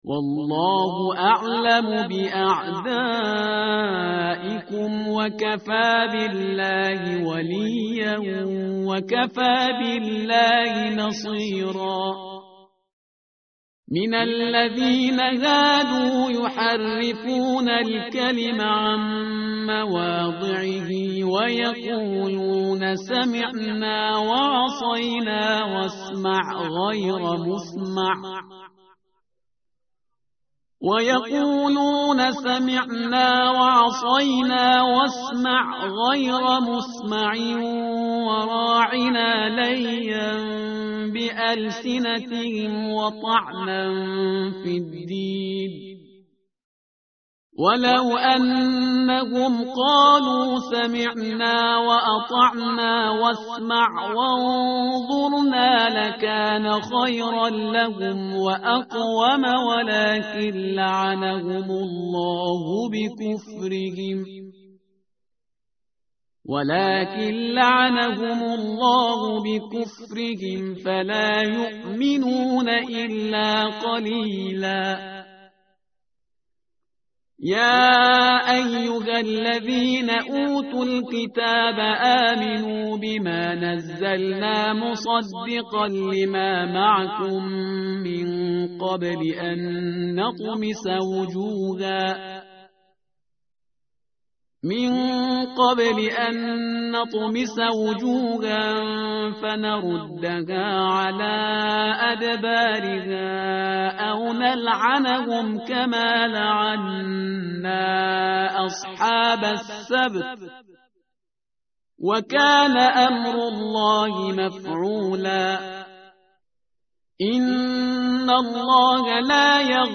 متن قرآن همراه باتلاوت قرآن و ترجمه
tartil_parhizgar_page_086.mp3